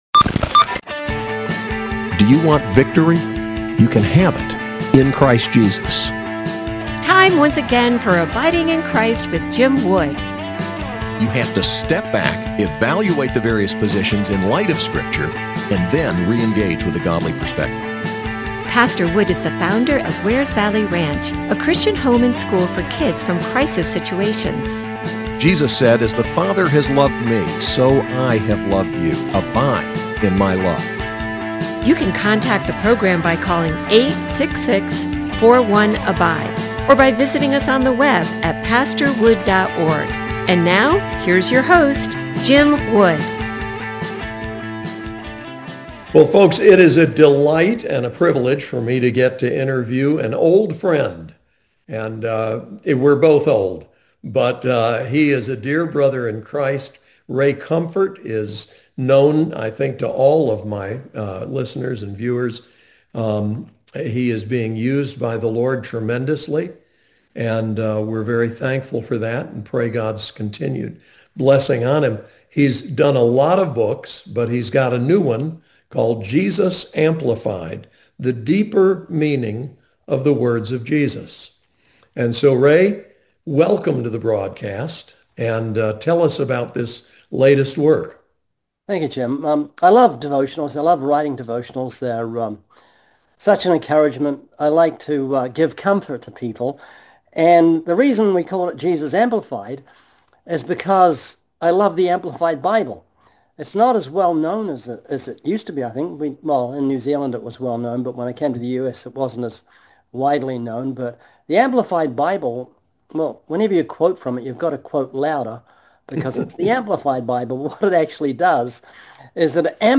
“Best Of” 2025 Interviews: Ray Comfort, Jesus Amplified